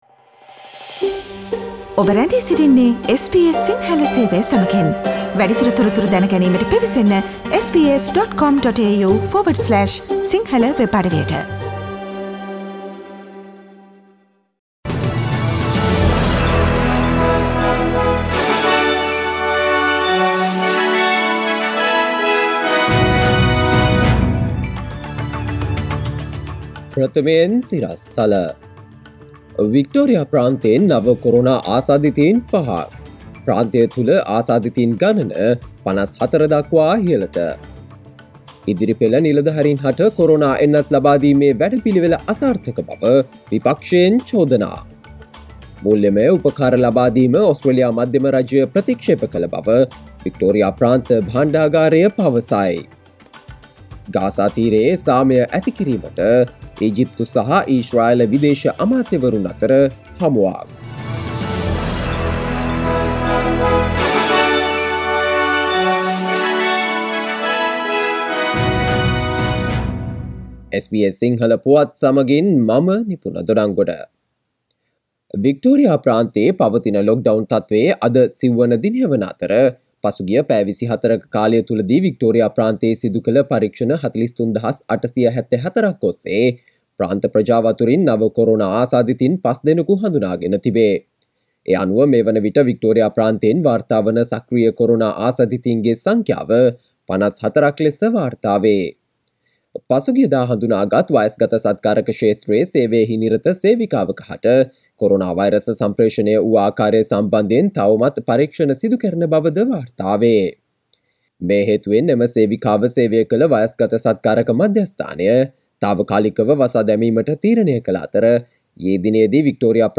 Here are the most prominent Australian and Sri Lankan news highlights from SBS Sinhala radio daily news bulletin on Monday 31 May 2021.